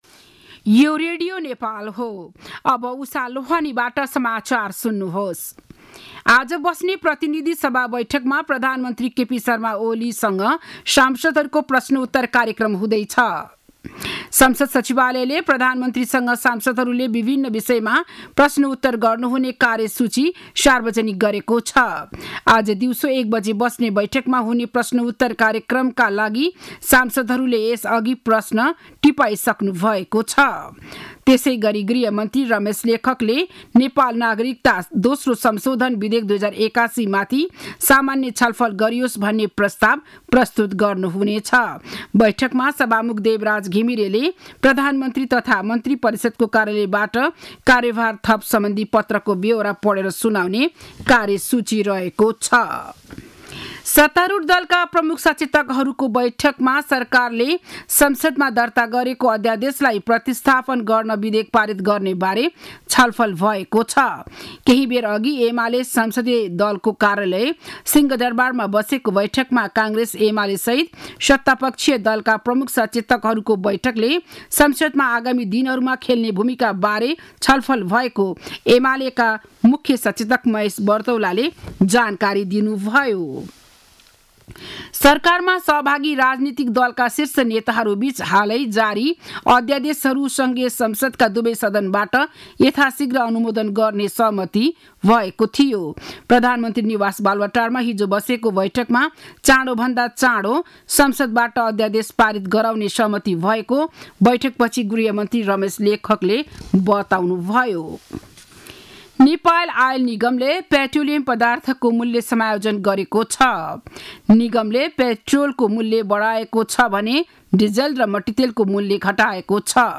बिहान ११ बजेको नेपाली समाचार : ५ फागुन , २०८१